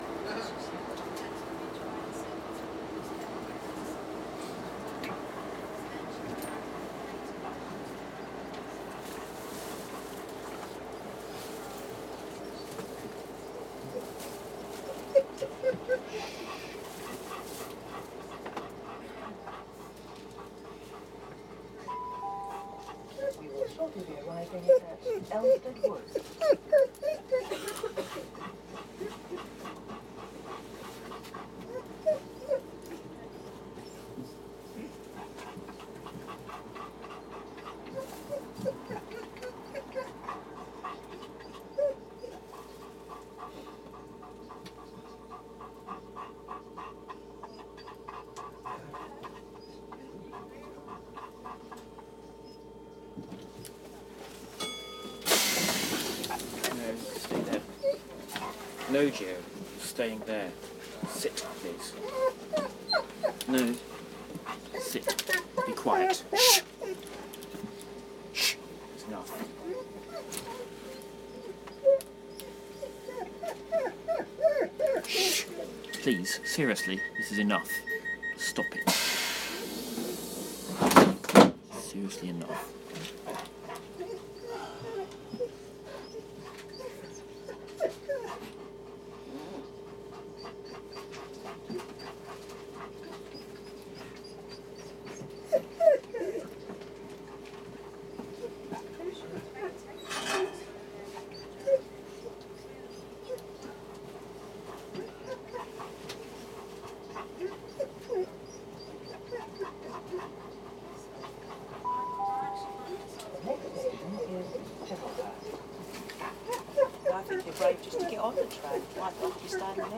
Gio seems to have taken to "singing" on public transport. Whether it is his chase instinct being activated by scenery passing outside, all the bells as the doors are unlocked, the experience seems to really excite his senses and motivate him to squeak. I will readily admit that it probably wasn't the best time to accept a request to stroke him, but at that moment I would have done anything to quieten him down or at least distract from the noise.